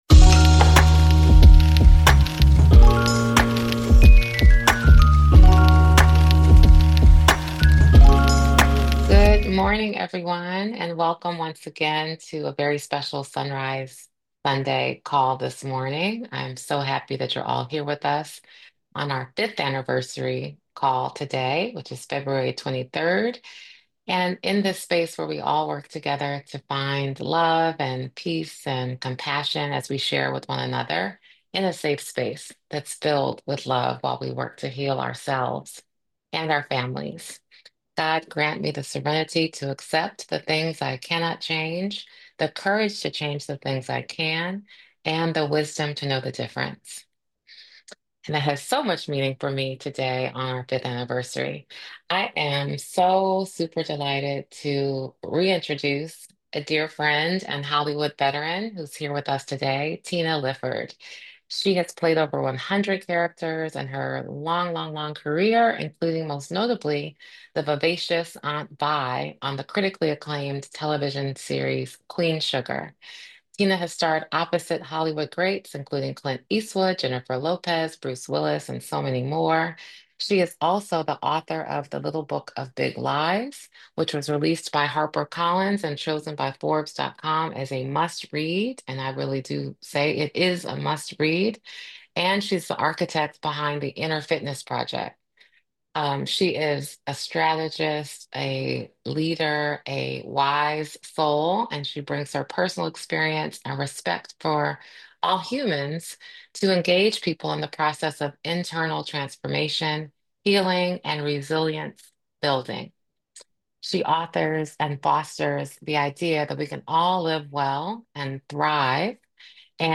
Join our celebration with a powerful conversation about resilience, grace, and the inner strength it takes to parent children with unique needs. Actress, author, and Inner Fitness founder Tina Lifford returns to share transformative insights from her upcoming book, The Inner Fitness Revolution.